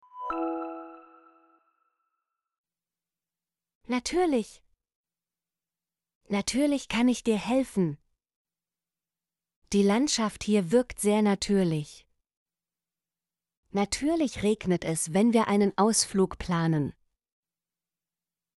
natürlich - Example Sentences & Pronunciation, German Frequency List